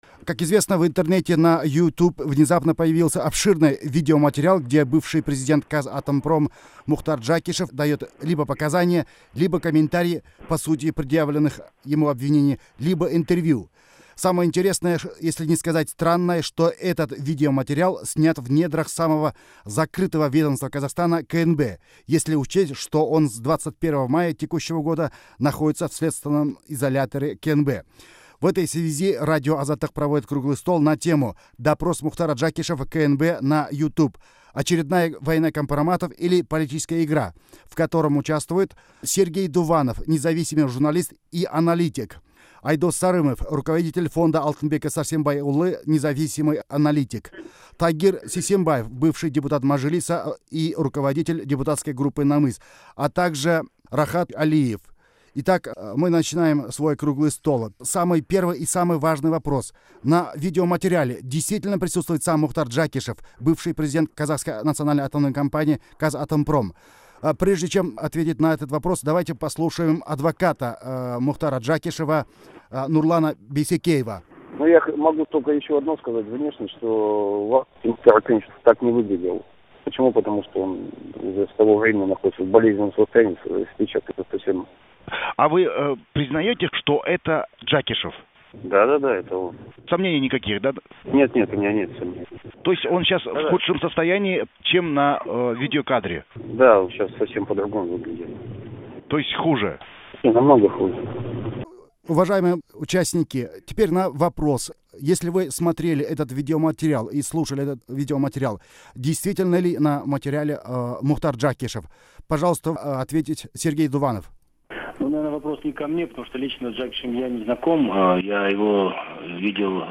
Мұхтар Жәкішев туралы орыс тіліндегі сұхбатты тыңдаңыз